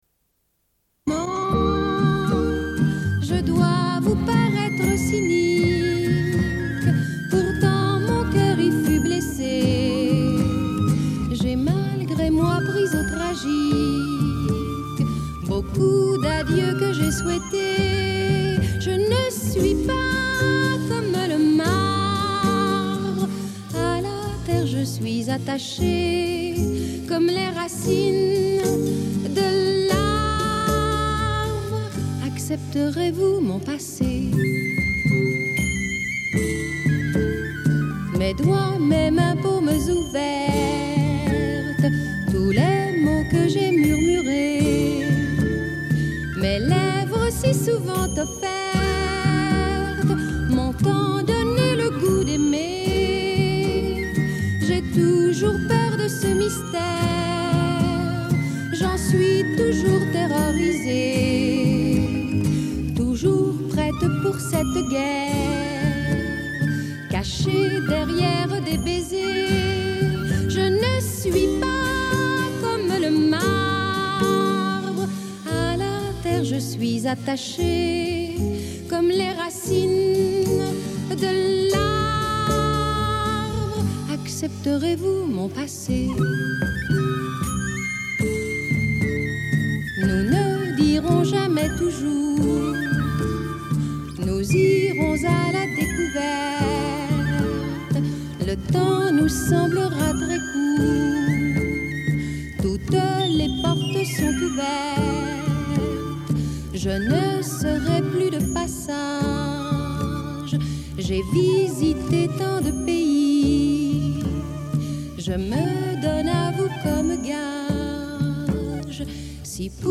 Une cassette audio, face B31:30